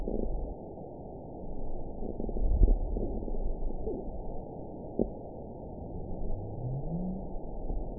event 917094 date 03/19/23 time 23:02:07 GMT (2 years, 1 month ago) score 9.69 location TSS-AB04 detected by nrw target species NRW annotations +NRW Spectrogram: Frequency (kHz) vs. Time (s) audio not available .wav